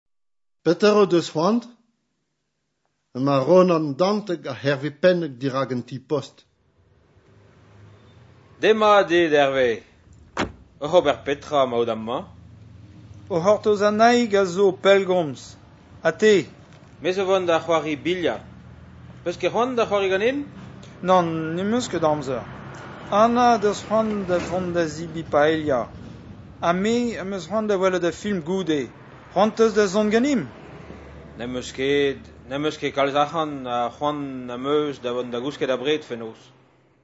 Divizioù